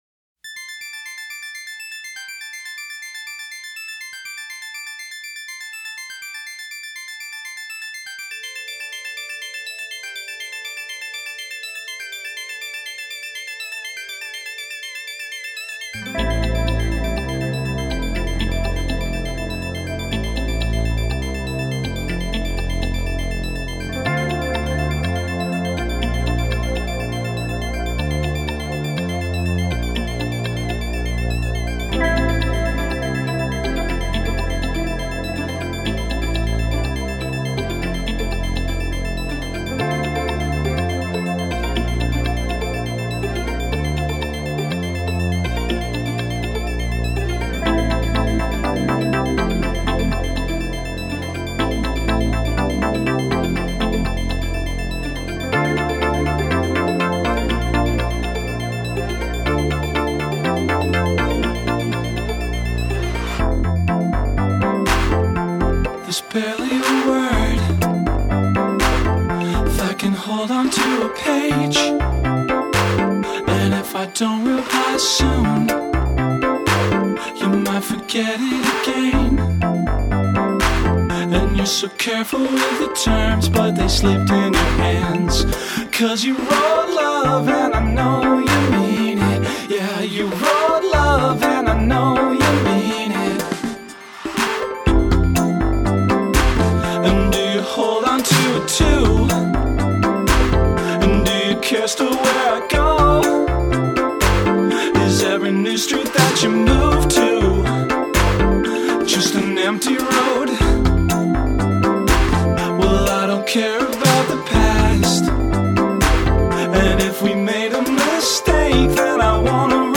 disco-esque vibed track